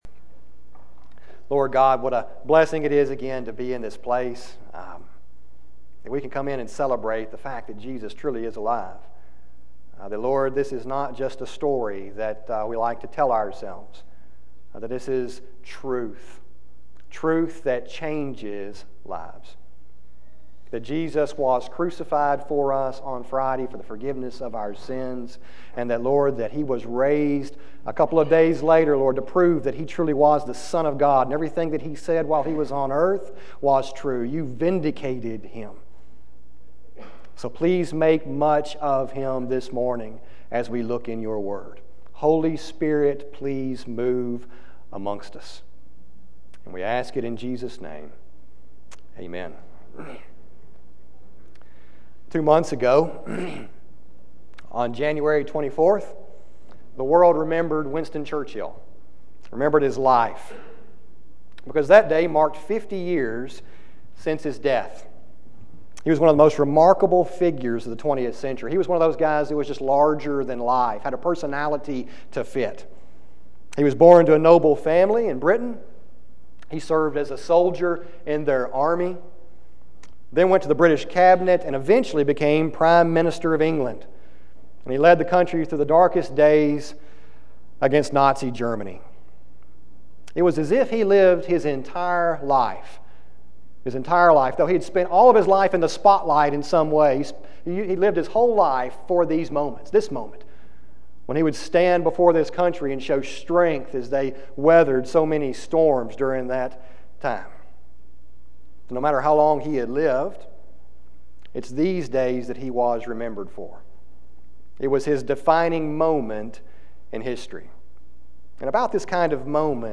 sermon040515b.mp3